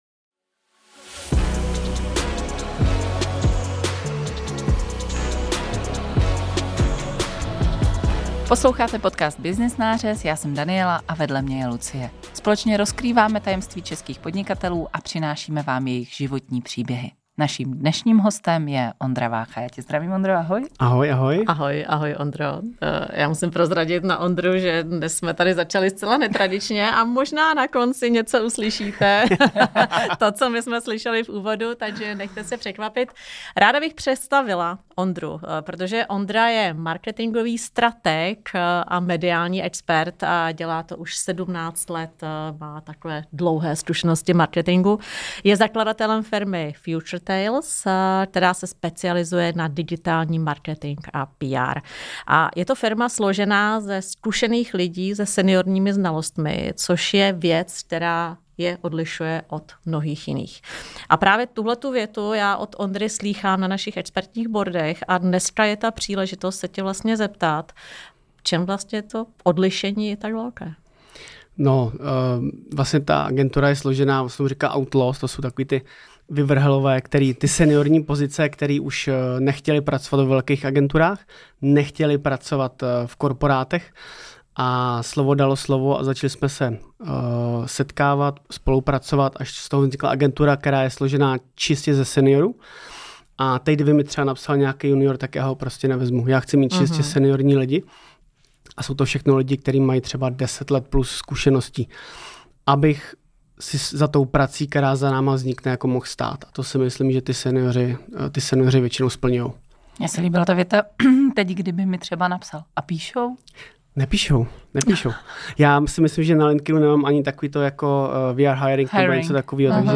Trendy v marketingu byly také v popředí naší diskuze s důrazem na význam umělé inteligence.